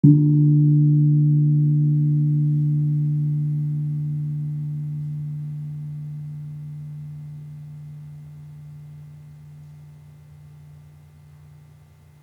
HSS_Gamelan-Pack1 / Gong
Gong-D2-p.wav